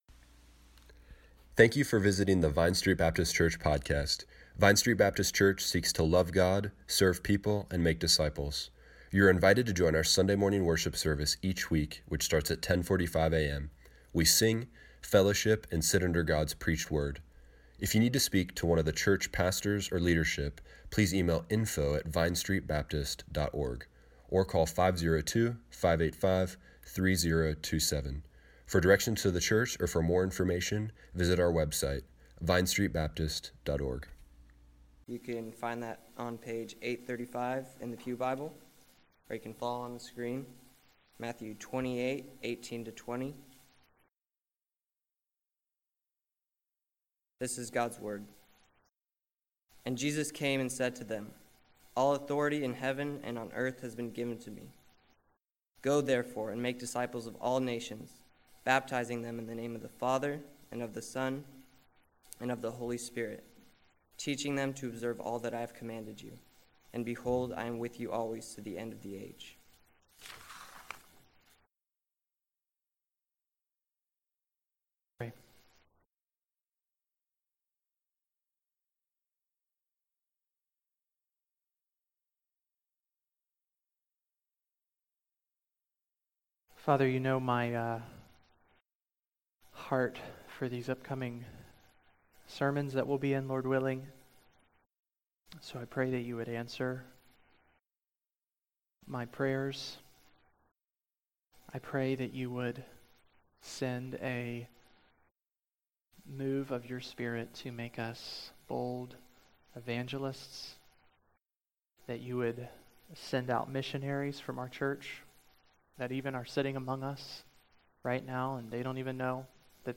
Missions Service Morning Worship